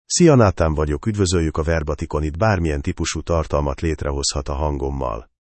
NathanMale Hungarian AI voice
Voice sample
Listen to Nathan's male Hungarian voice.
Male
Nathan delivers clear pronunciation with authentic Hungary Hungarian intonation, making your content sound professionally produced.